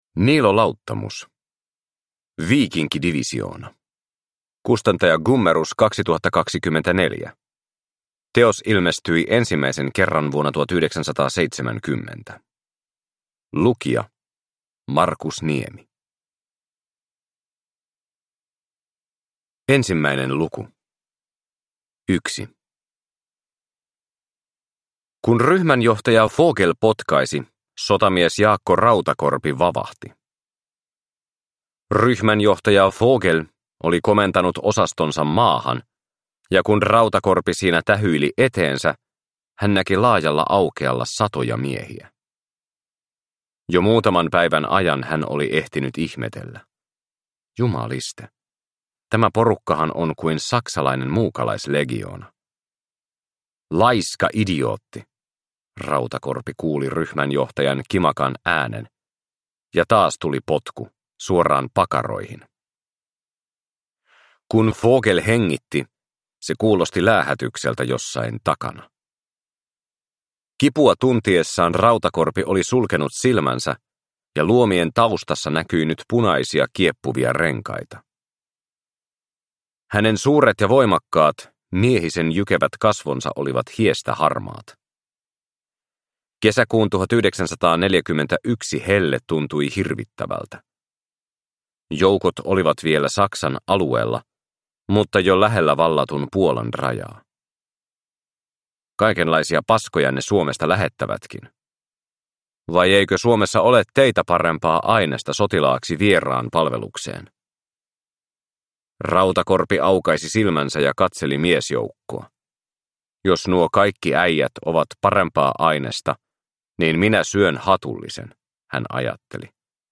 Viikinkidivisioona (ljudbok) av Niilo Lauttamus